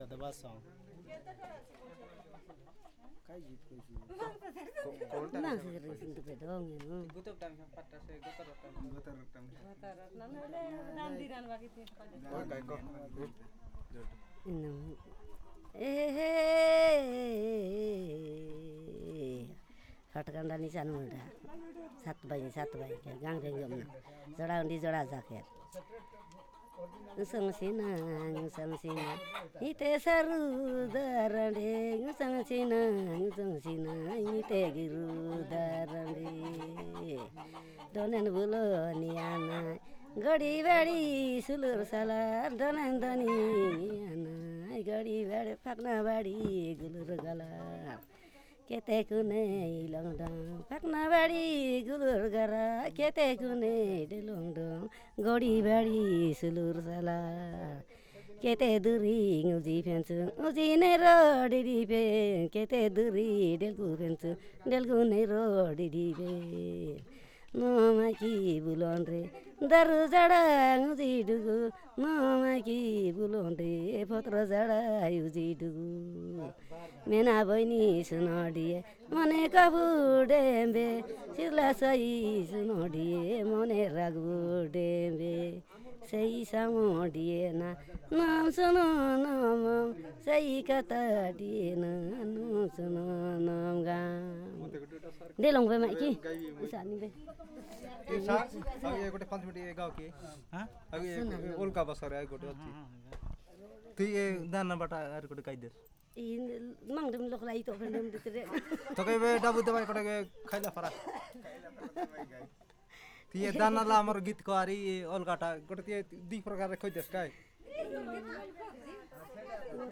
Perfomance of marriage song